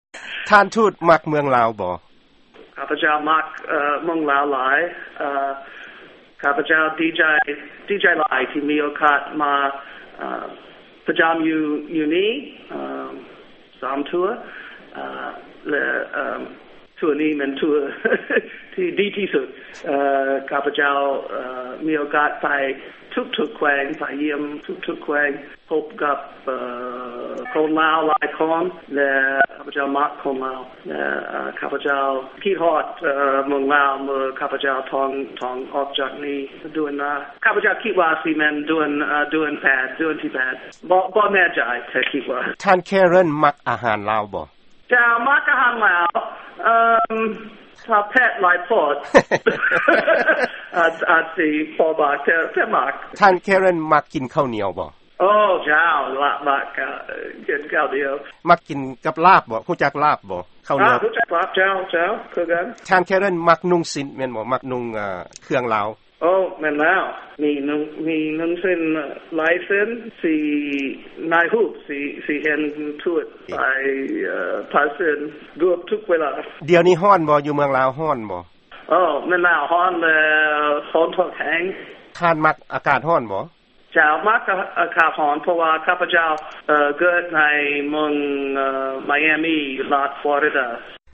ຟັງການສໍາພາດ ທ່ານທູດ ແຄເຣັນ ເວົ້າພາສາລາວ